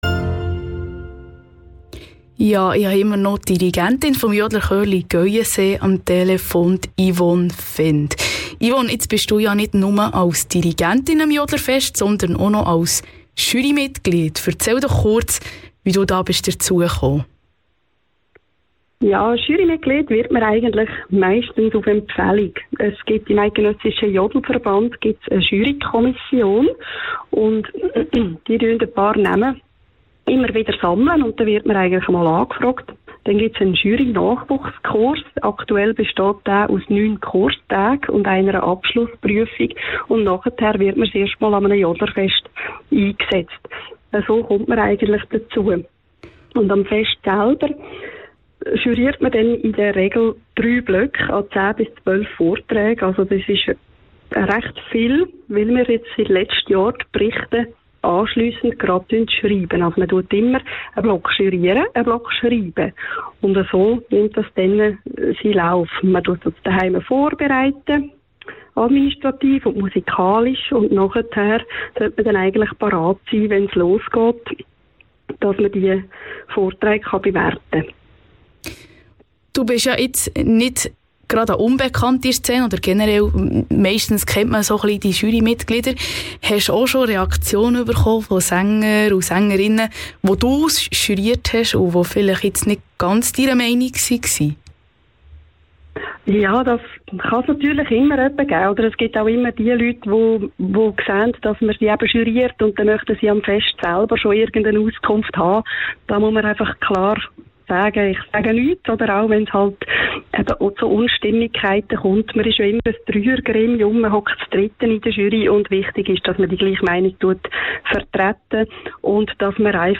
Report Radio Beo 06/23
Interview mit Jurymitglied